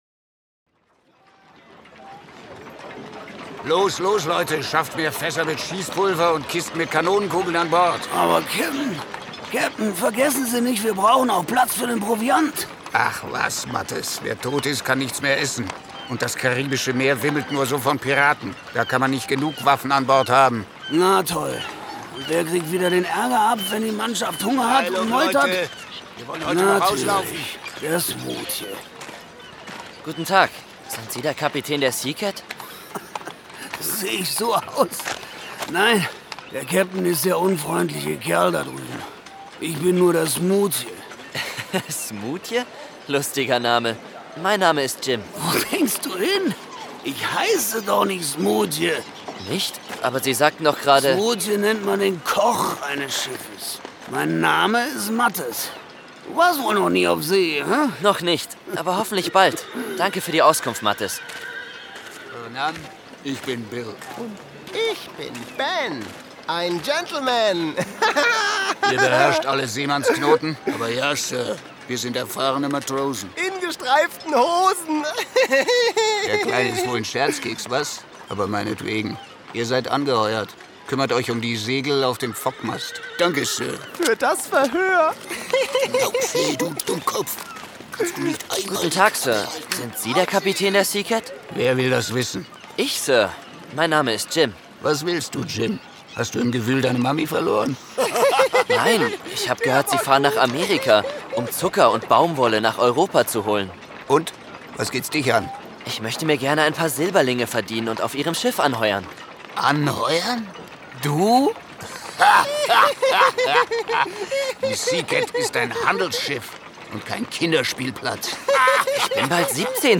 WAS IST WAS Junior Hörspiel: Piraten Lustige Reime, Musik und Themensong